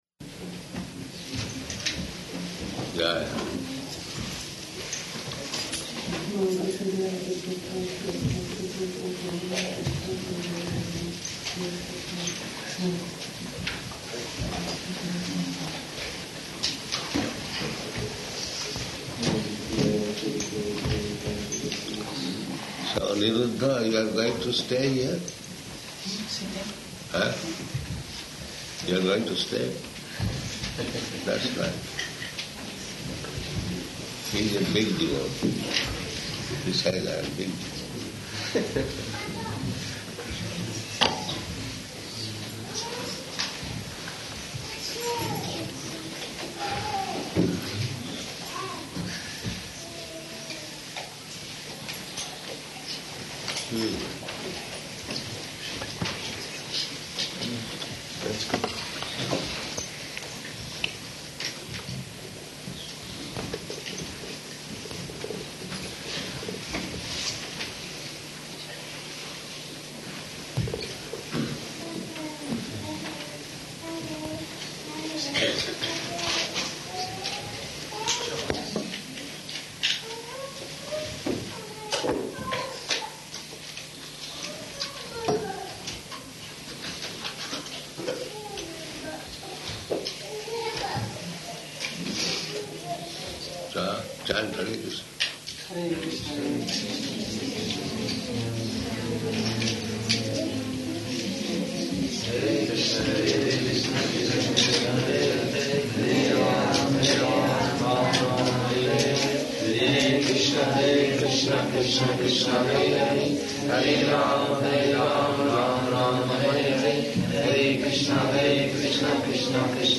Room Conversation
Room Conversation --:-- --:-- Type: Conversation Dated: August 2nd 1976 Location: New Māyāpur Audio file: 760802R2.NMR.mp3 Prabhupāda: Jaya.
[devotees and gurukula children chant the mahā-mantra in unison] Prabhupāda
[chanting becomes louder and faster, with clapping] [chanting ends] [children laughing] Prabhupāda: If possible.